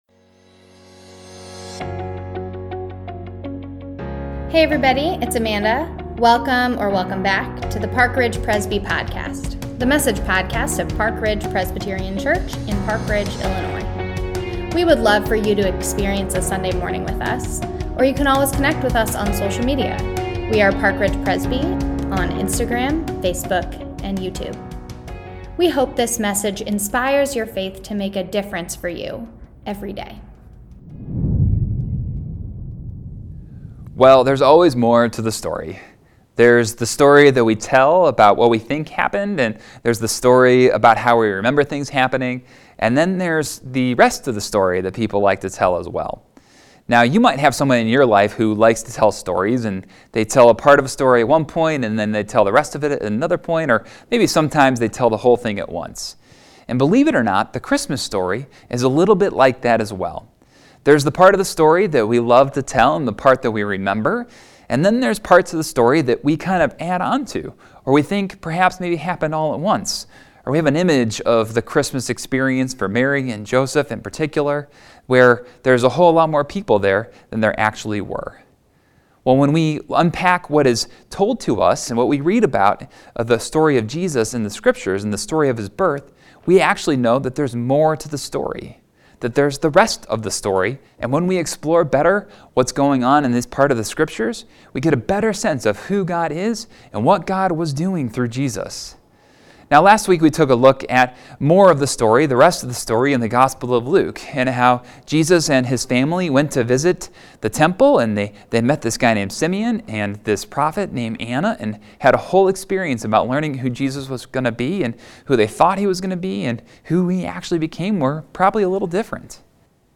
10am Online Service | The rest of the Christmas story! | January 2nd 2022 | Church Worship!